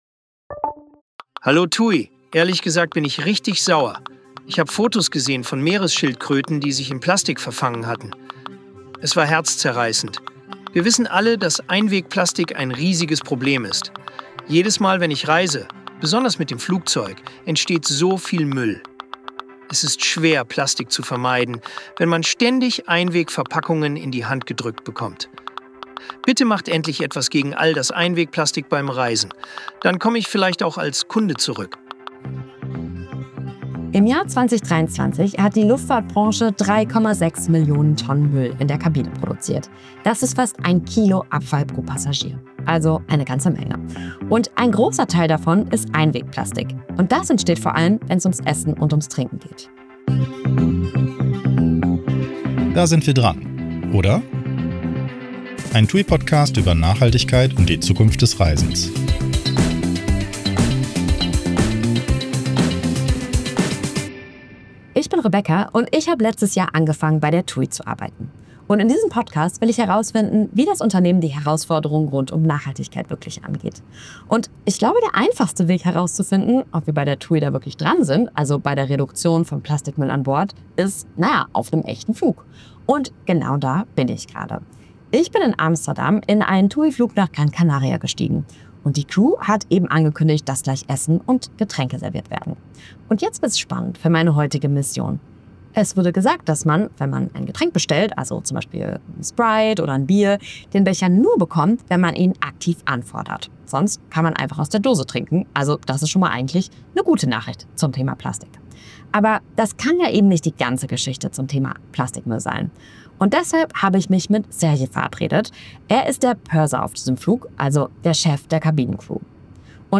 Die Antworten der interviewten Person in dieser Folge wurden mit Hilfe von Künstlicher Intelligenz übersetzt und redaktionell bearbeitet.